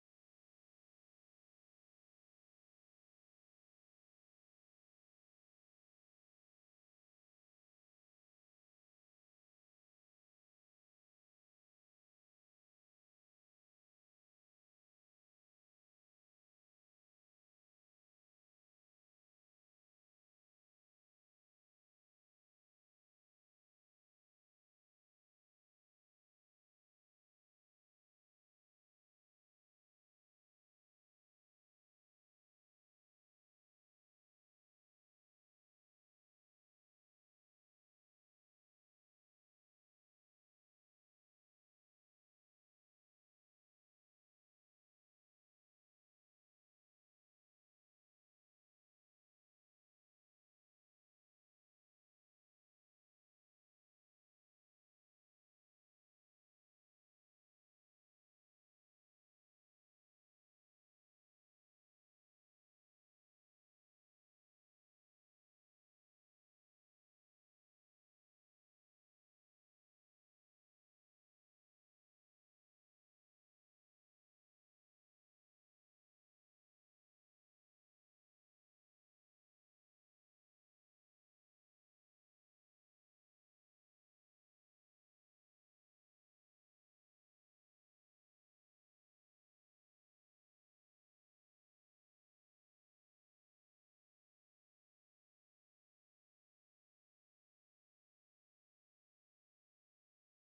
挿入歌２の２